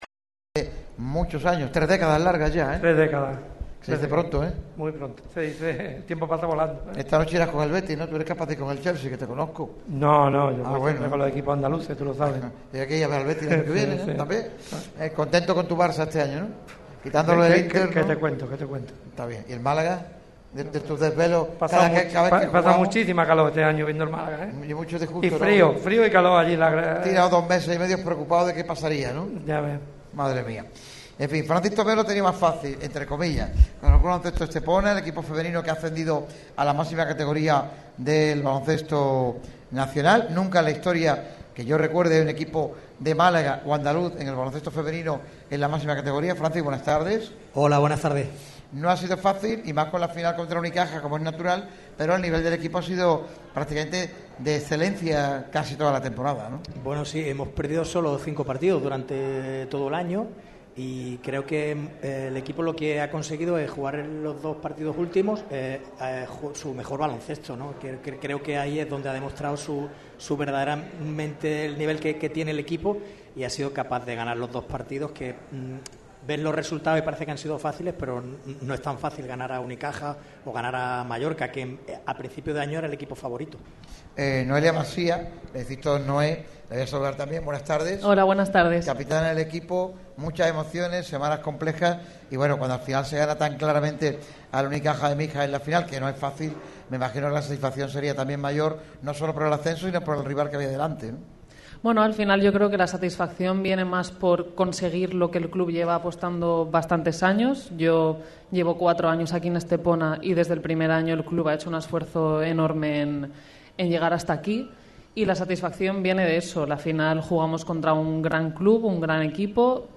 En el programa especial de Radio MARCA Málaga ubicado en Cervezas Victoria se ha querido reconocer el valor de un ascenso histórico para la provincia